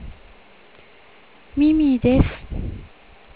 Macにマイクをさしこんで、「SoundMachine」というツールを使って作ります。